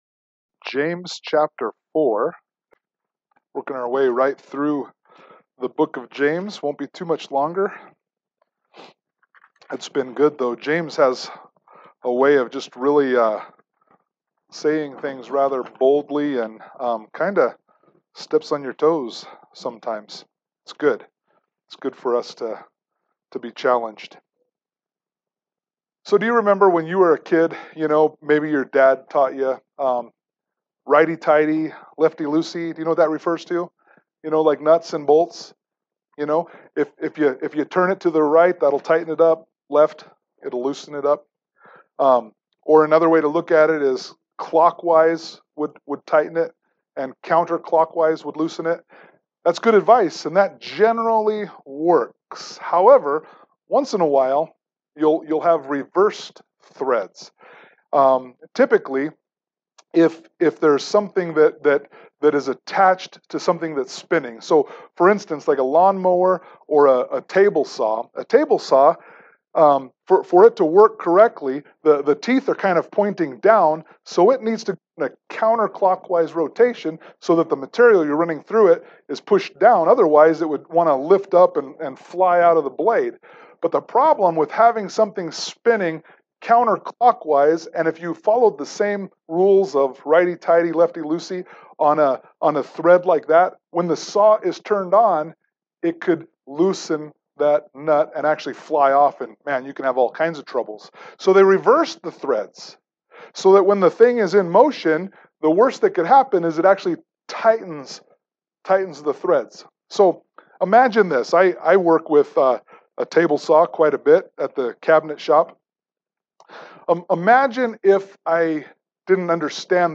James 4:7-10 Service Type: Sunday Morning Worship « James 4:1-6